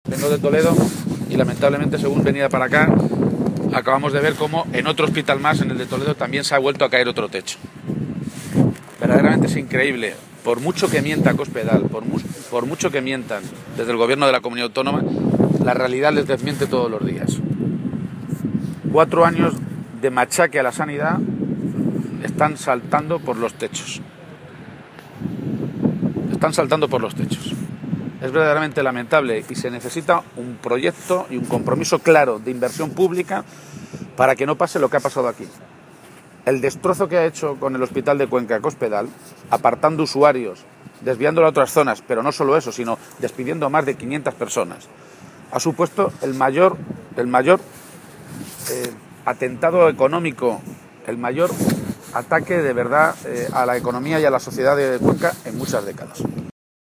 García-Page se pronunciaba de esta manera esta mañana, en Cuenca, en una comparecencia ante los medios de comunicación, en la que aseguraba que lo más triste de este tipo de cosas (derrumbes de techos de hospitales y despidos como ejemplo del desmantelamiento de los servicios públicos esenciales) es que han ocurrido no solo después de que Cospedal prometiera que no iba a traspasar las líneas rojas de sus recortes en la Sanidad o la Educación, sino “después de que ver cómo vamos a terminar la legislatura con 6.000 millones de euros más de deuda pública. Es decir, que con Cospedal hemos acumulado más deuda pública en tres años que en los treinta anteriores de la historia” de Castilla-La Mancha.